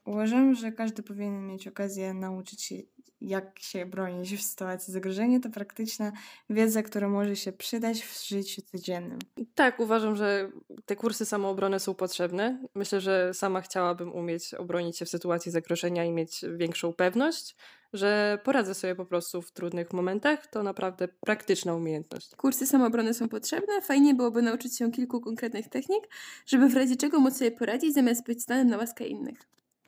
Zapytaliśmy studentów czy uważają, że kursy samoobrony są potrzebne: